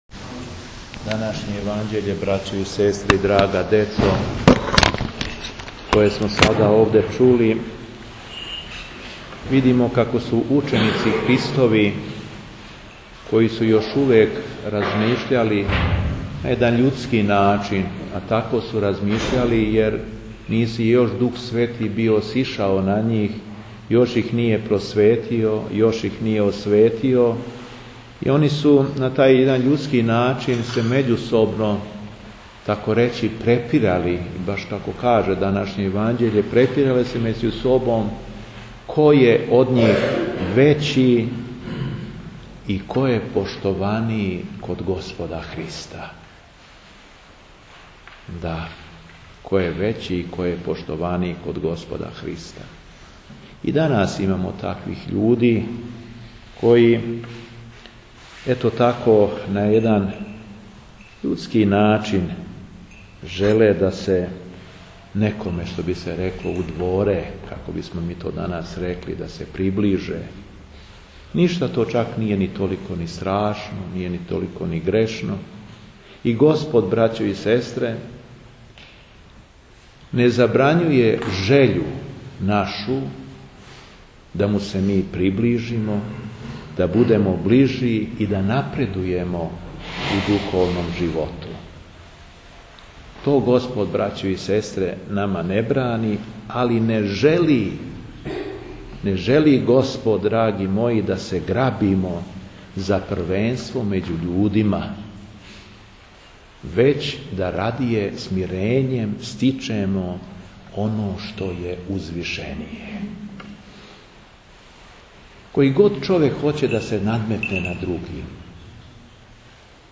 Беседа епископа шумадијског Г. Јована